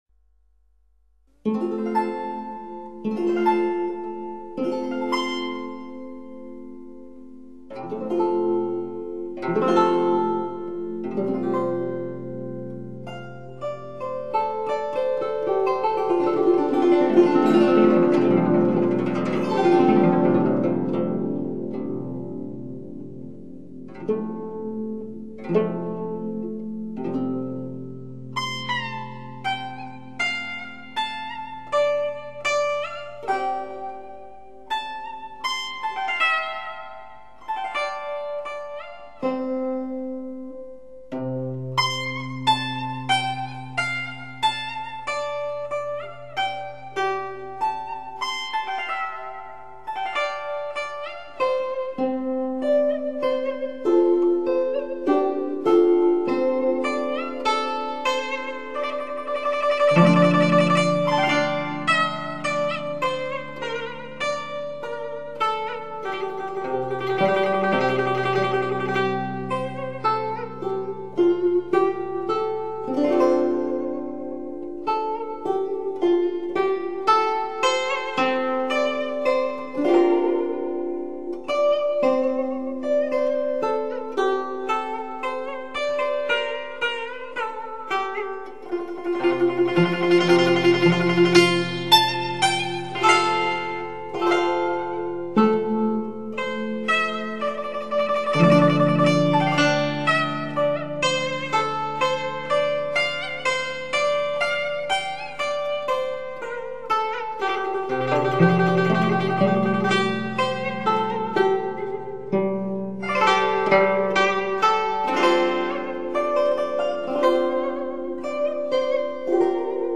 古筝，弹弦乐器。
特有动人心弦而又激昂震撼之音色，演译不朽经典旋律，予人万马千军感受...